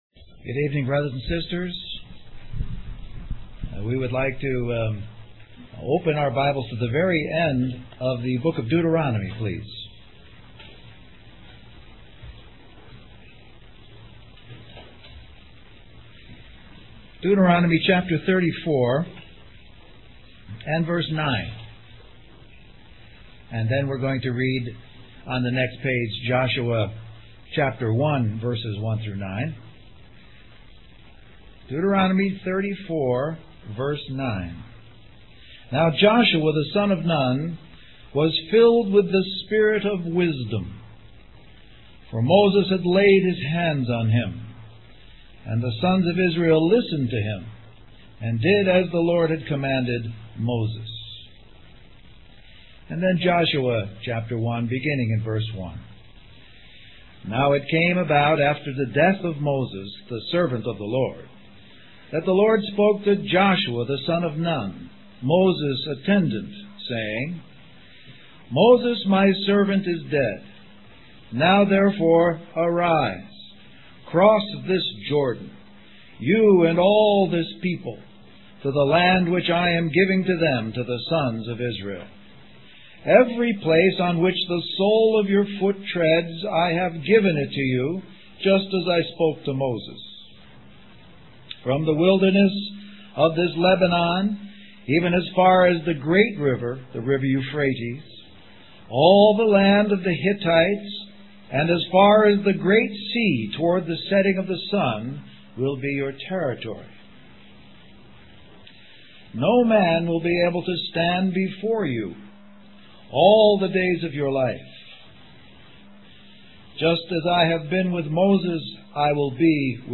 Western Christian Conference